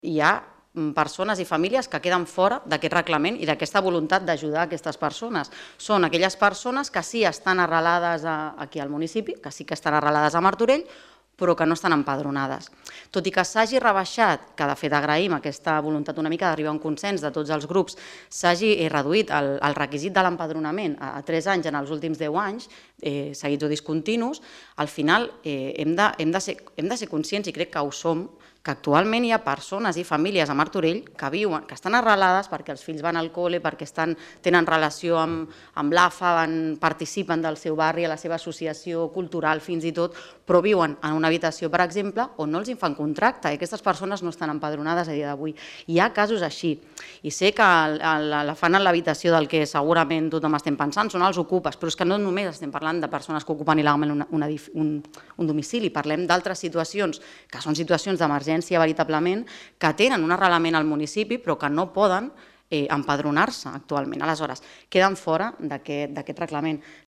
Laura Ruiz, portaveu Movem Martorell